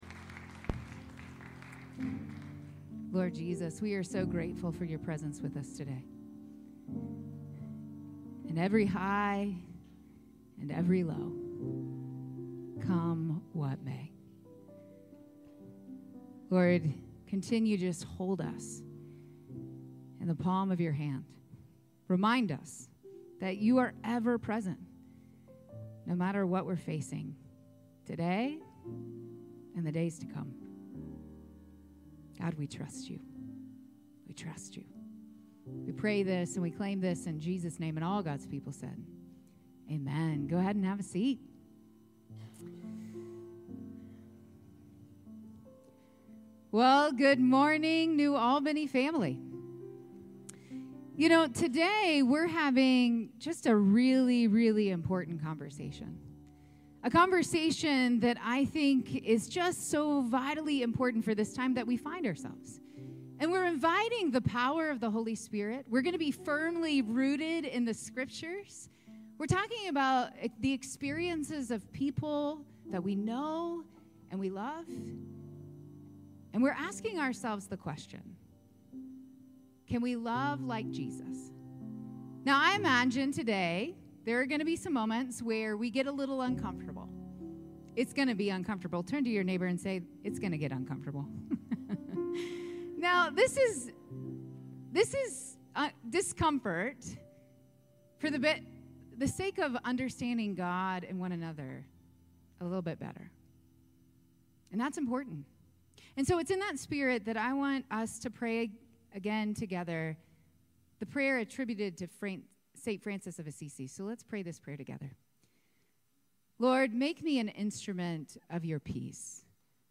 9:30 Redemption Worship Service 02/09/25